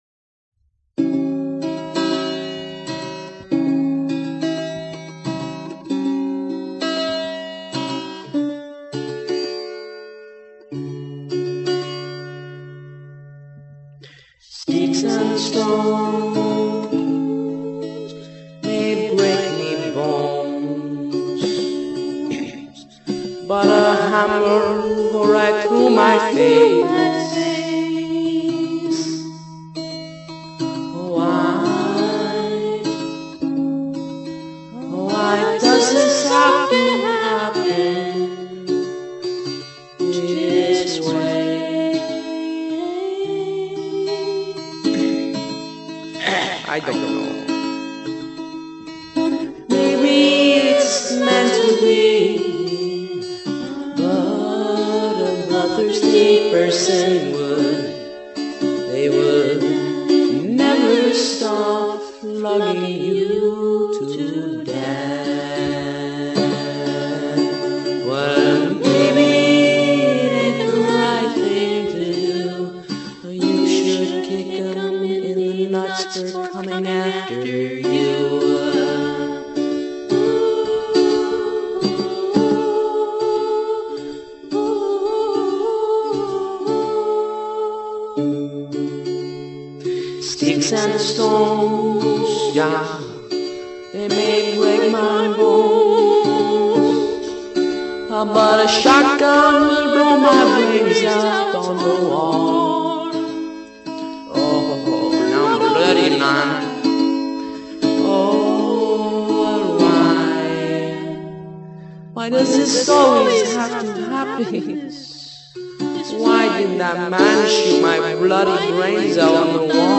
simon and garfunkle sound-a-like
very serene....and sometimes funny